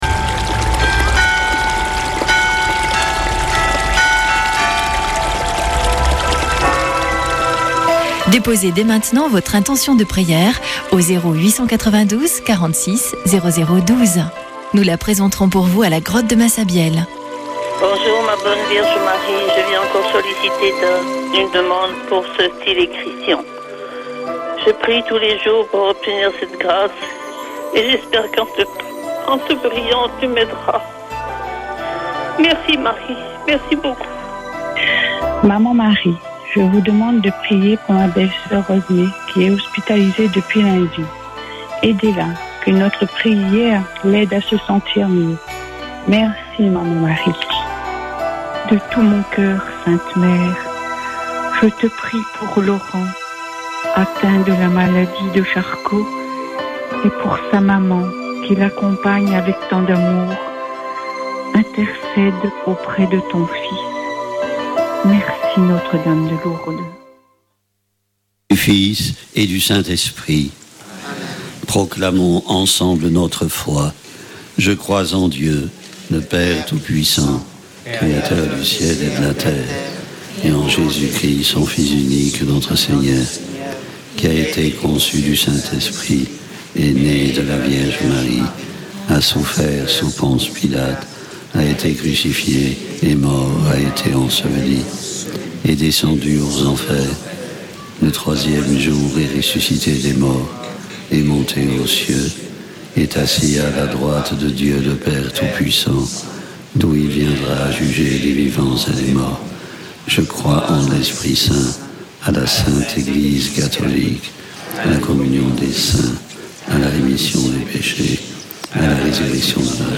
Prière et Célébration
Une émission présentée par Chapelains de Lourdes